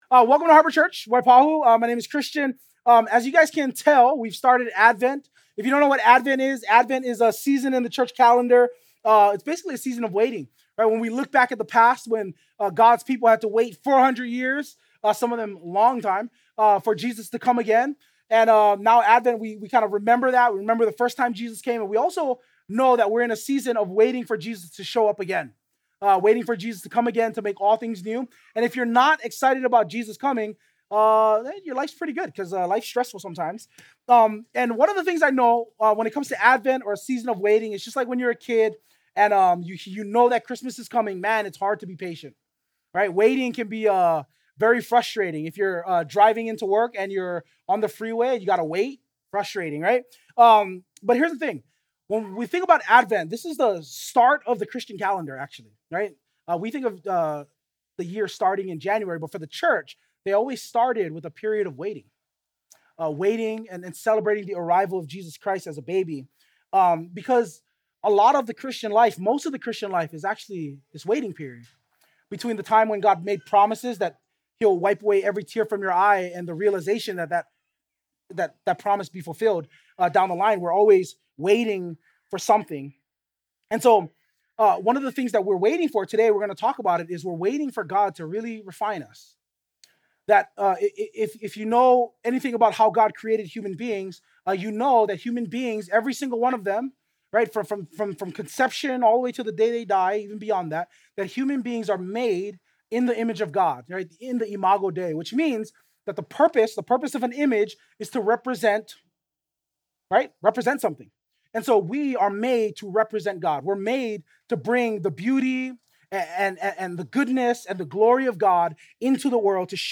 2025 Expecting Refinement Preacher